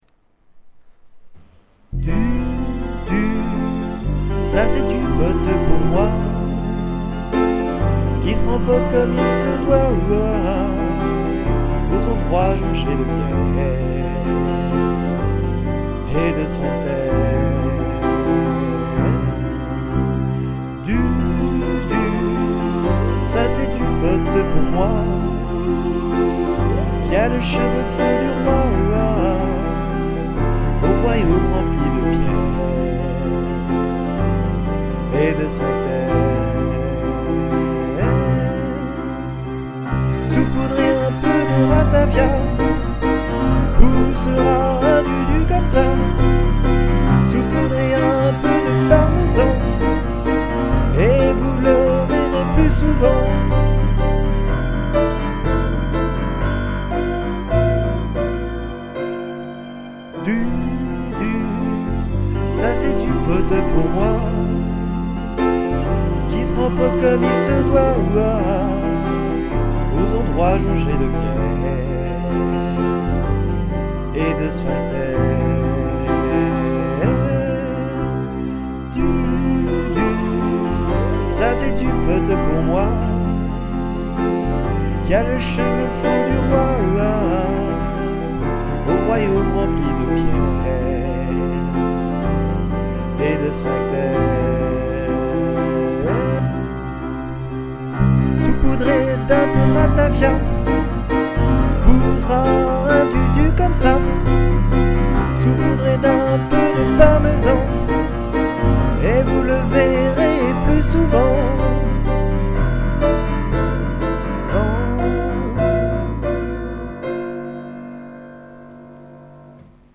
Rif piano sur partie #1 et #2
Rif piano sur refrain
• Synthé: KORG T3ex
• Piano: Sauter